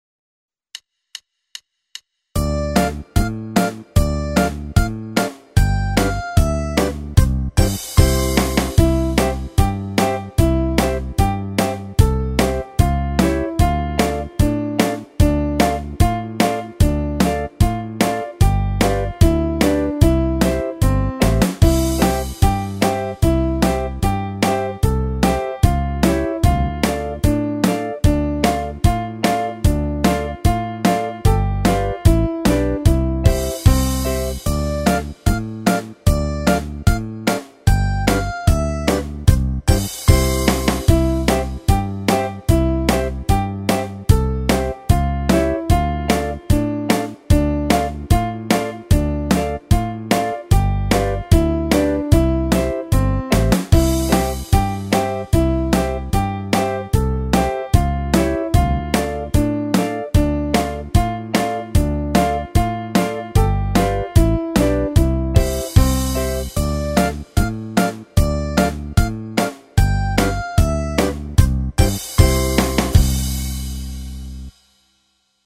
- aranżacje do ćwiczeń gry na dzwonkach: